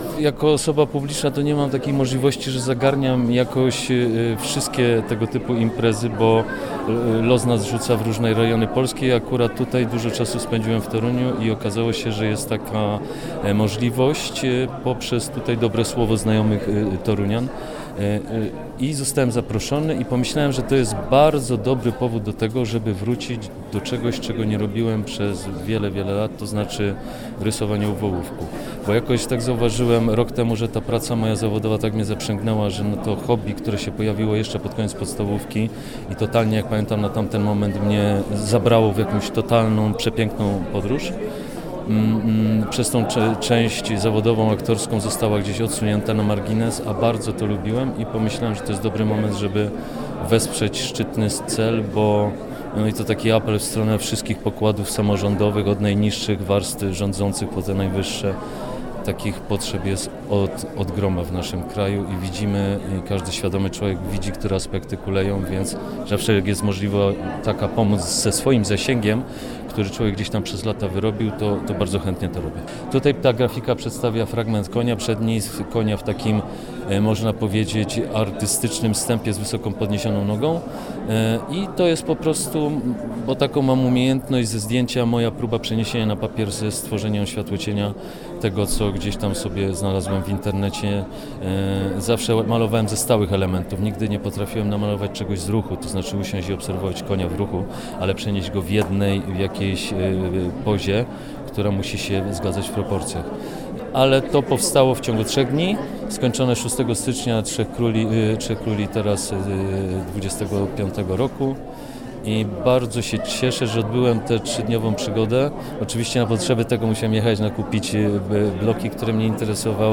Lukasz-Simlat-aktor.mp3